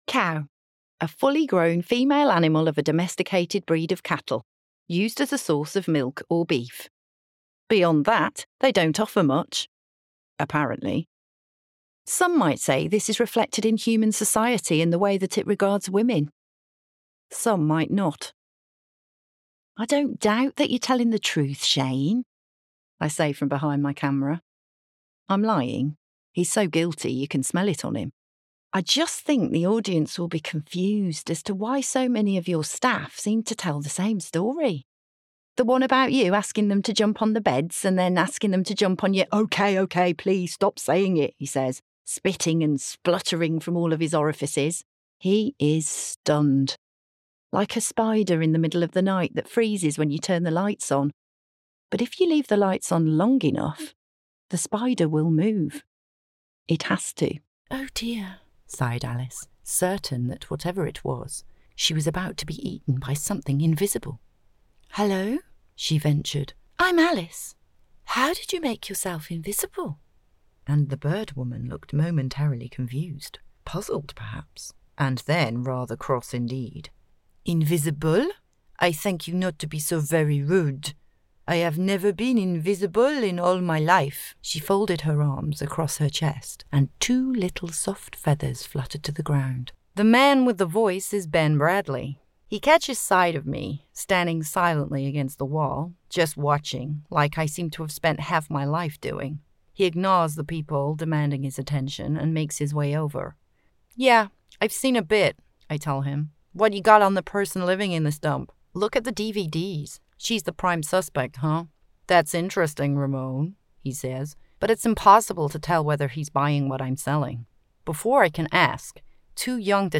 • Native Accent: Black Country
• Home Studio